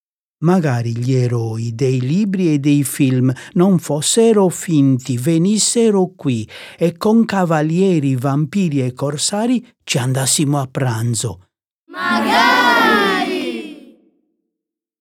La sequenza completa e continua delle 23 quartine, coi loro cori a responsorio, è offerta in coda al libro con un QR-code.